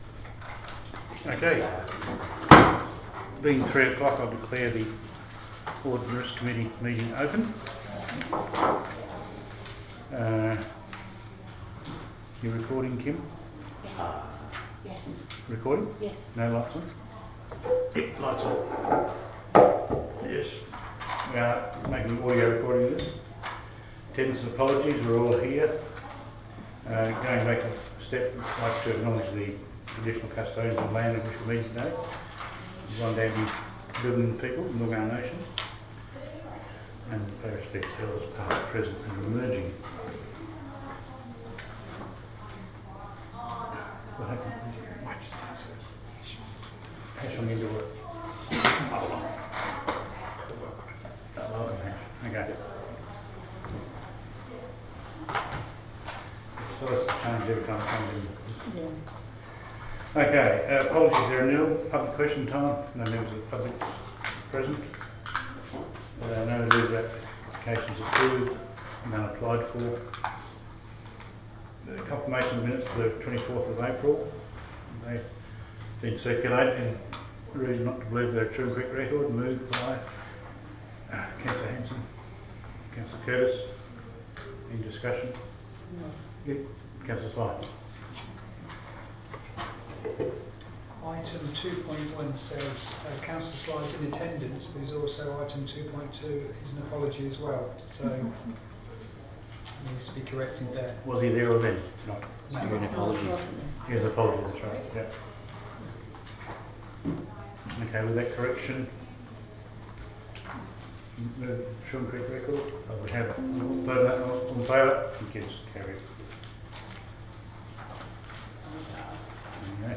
recording-audit-and-risk-advisiory-committee-meeting-8-may-2025.mp3